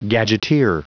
Prononciation du mot gadgeteer en anglais (fichier audio)
gadgeteer.wav